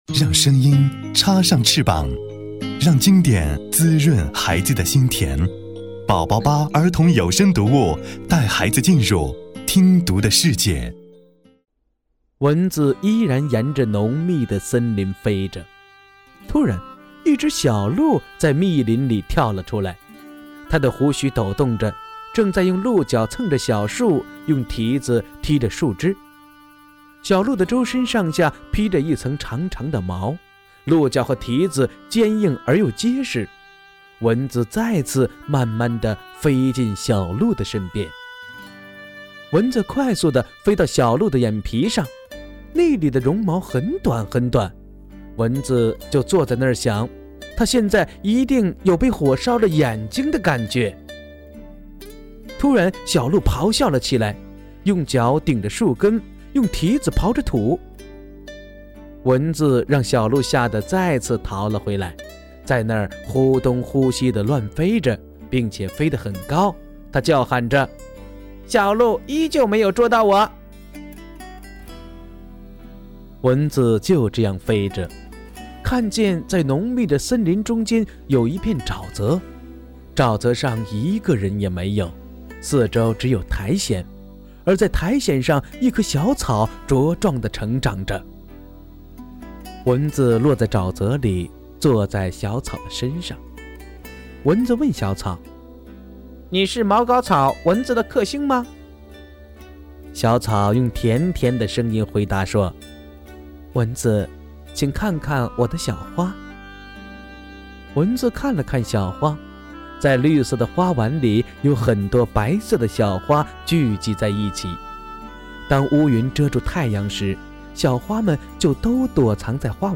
首页>mp3 > 儿童故事 > 08茅膏草--蚊子的克星_1(你知道的和不知道的)